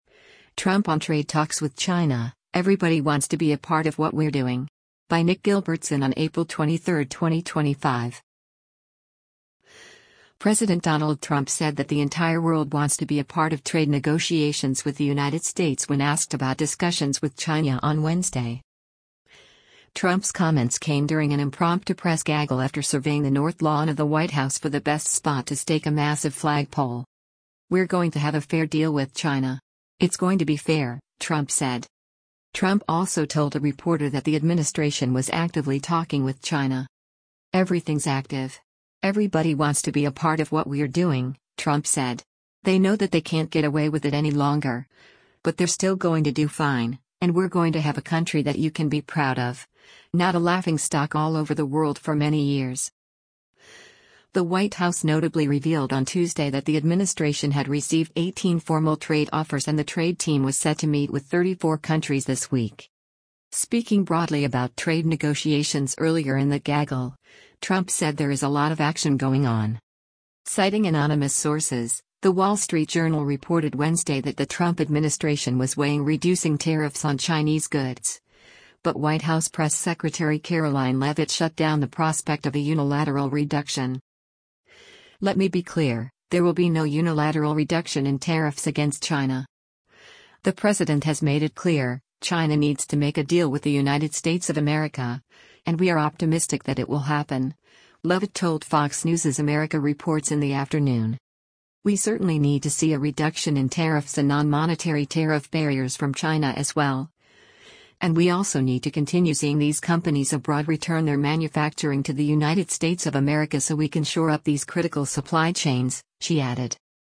Trump’s comments came during an impromptu press gaggle after surveying the North Lawn of the White House for the best spot to stake a massive flag pole.